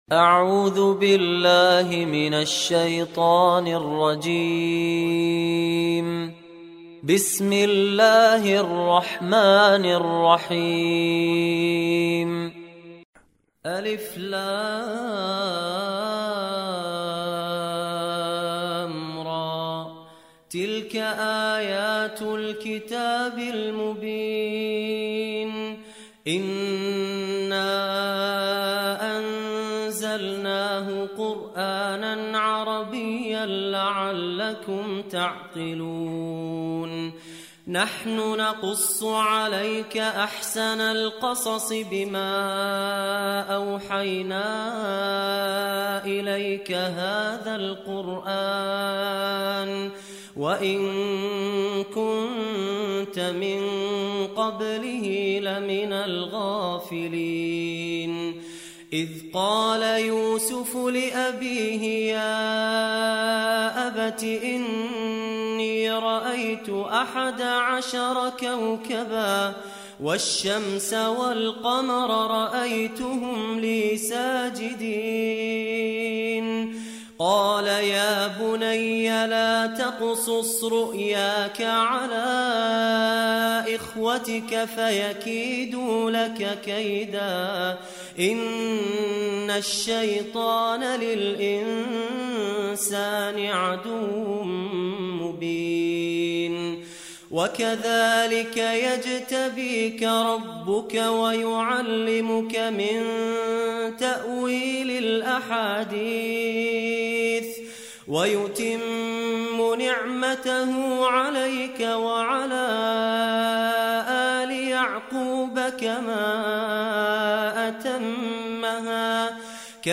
دانلود سوره يوسف mp3 فهد الكندري روایت حفص از عاصم, قرآن را دانلود کنید و گوش کن mp3 ، لینک مستقیم کامل